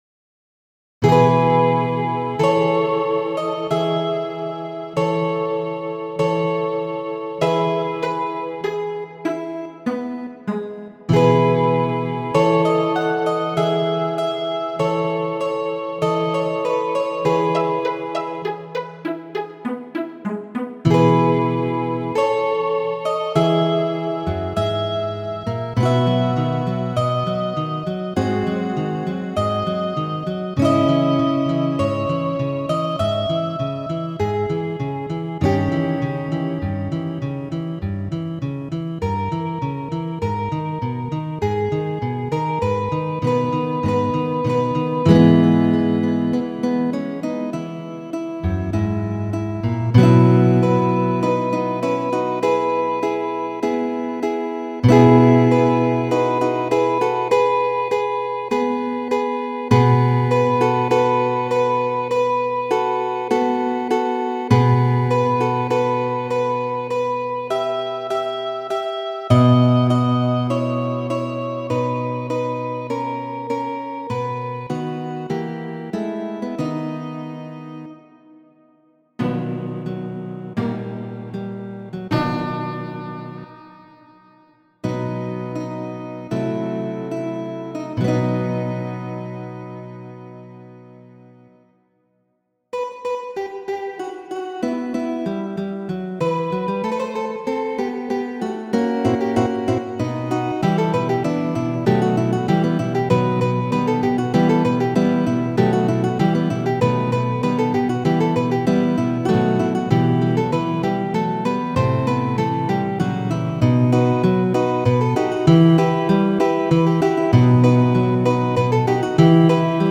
Fandango, de la Kvinopo kun gitaro de Bokerini (1743-1805).